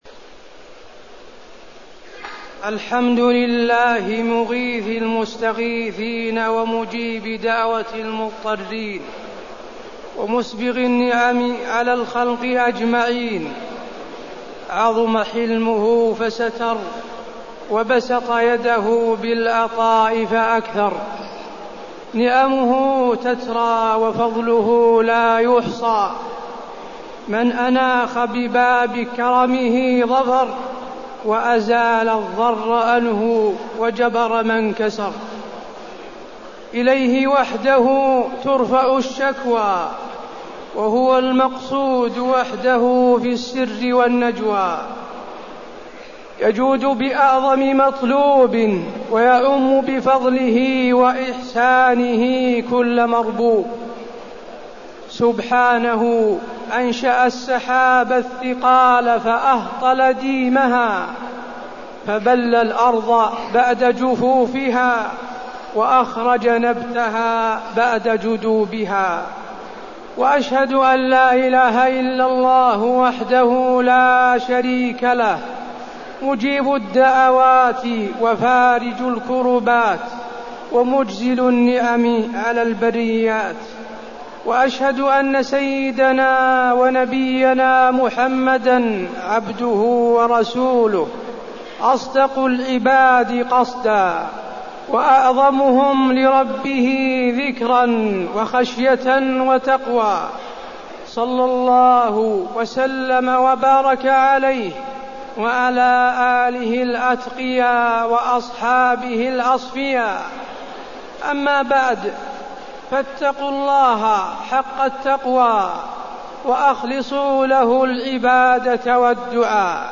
خطبة الاستسقاء - المدينة- الشيخ حسين آل الشيخ
المكان: المسجد النبوي